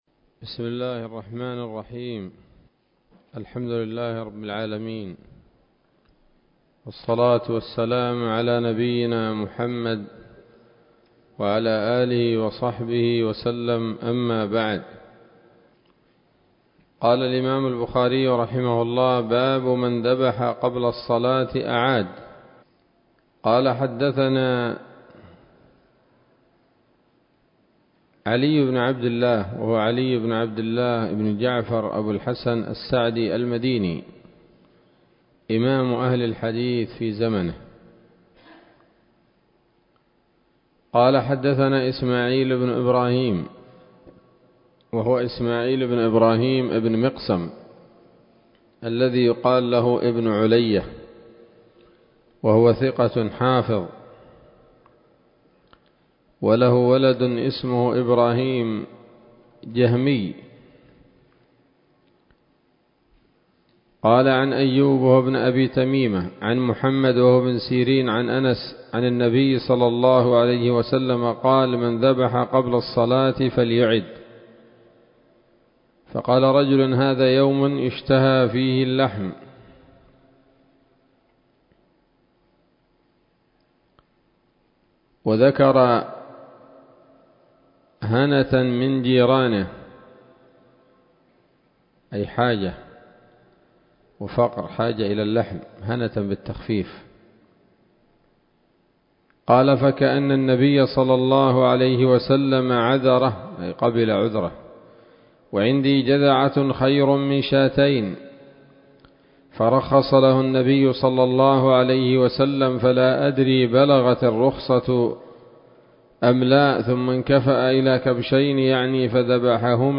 الدرس الحادي عشر من كتاب الأضاحي من صحيح الإمام البخاري